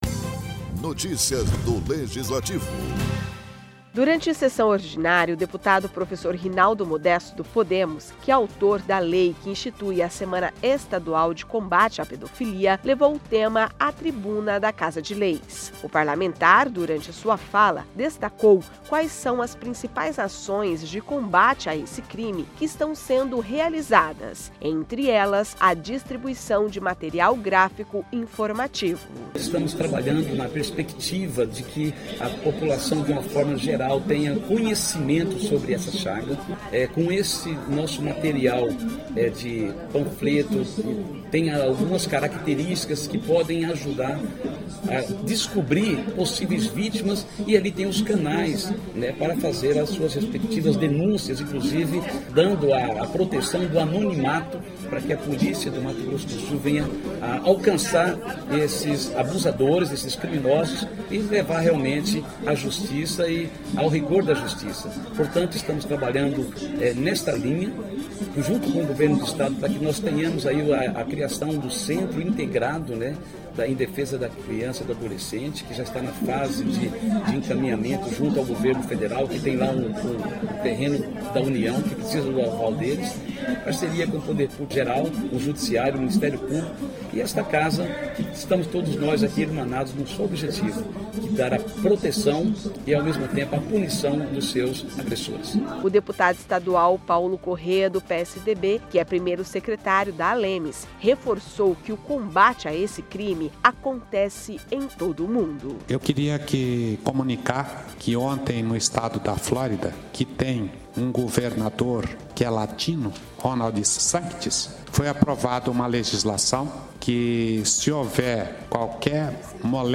Durante sessão ordinária o deputado professor Rinaldo Modesto (Podemos), que é autor da Lei que instituiu a Semana Estadual de Combate à Pedofilia, levou o tema a tribuna da Casa de Leis.